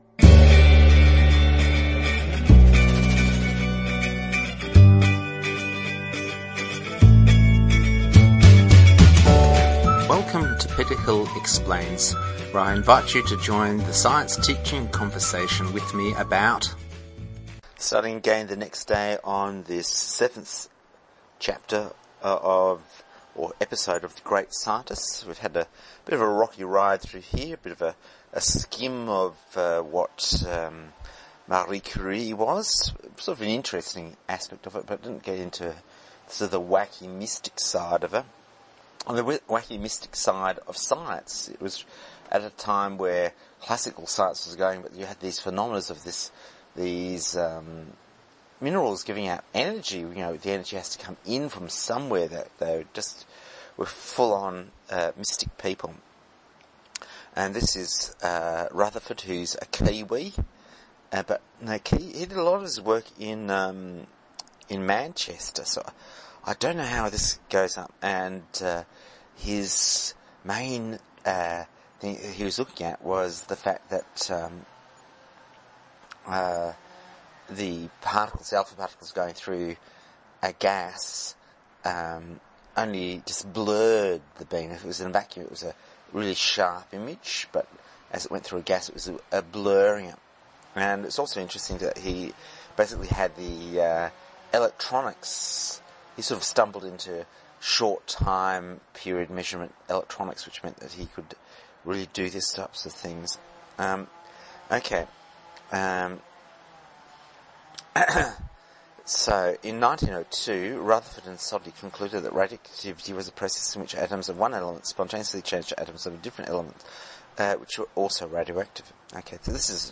Read over two days we have Marie Curie.